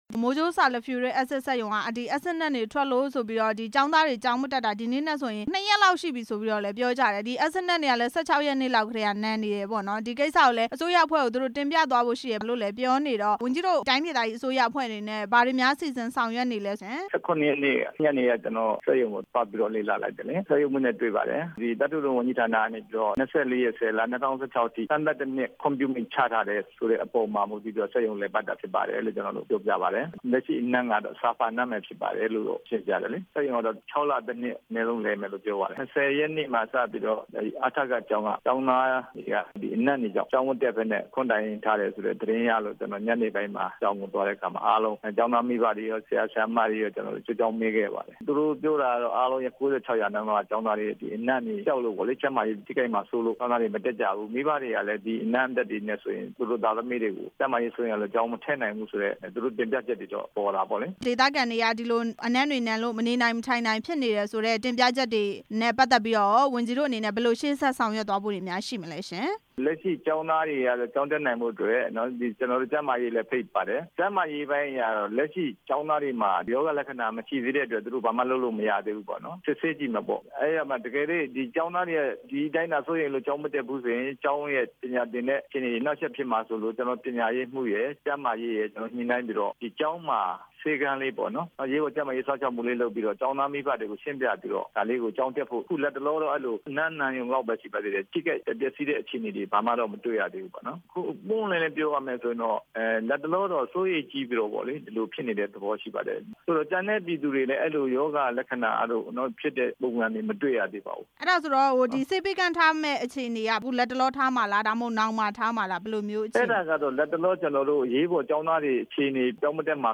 အက်ဆစ်စက်ရုံကို သွားရောက် စစ်ဆေးခဲ့တဲ့ စစ်ကိုင်း စည်ပင်ဝန်ကြီးနဲ့ မေးမြန်းချက်